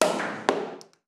Tapón de una botella de cava